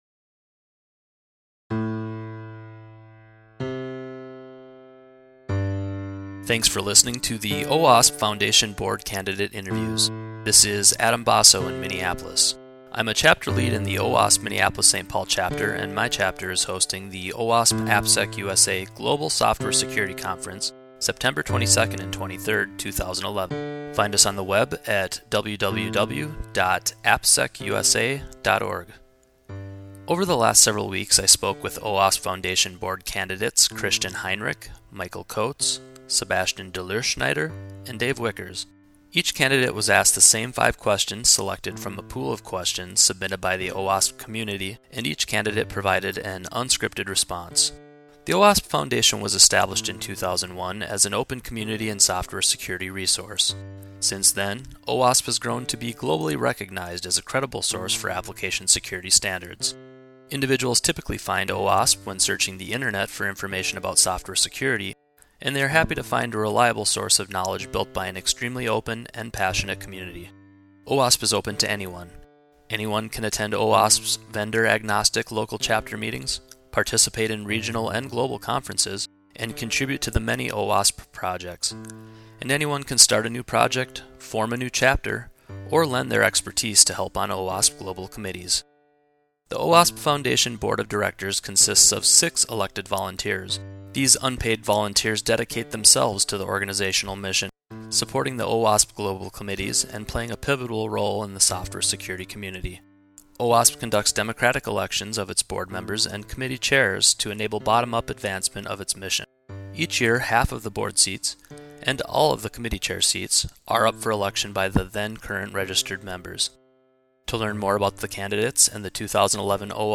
owasp_foundation_2011_board_candidate_interviews.mp3